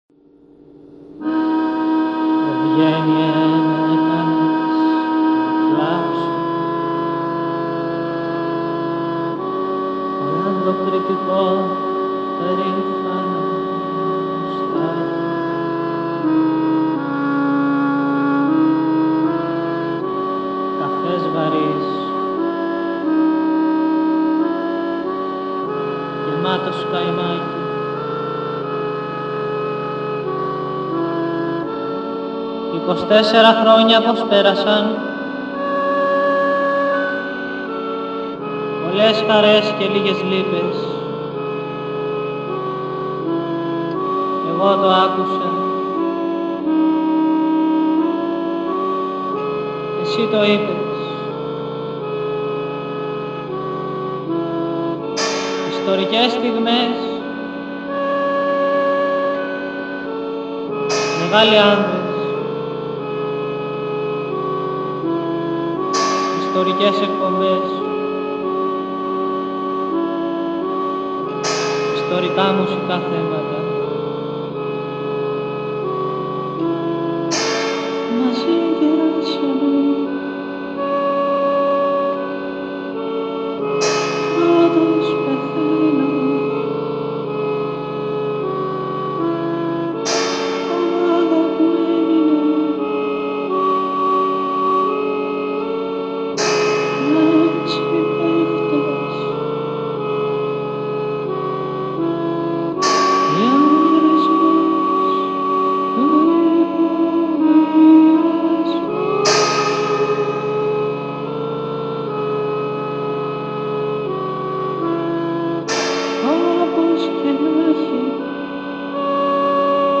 Σημείωση: Η κακή ποιότητα του ήχου δεν οφείλεται στα mp3.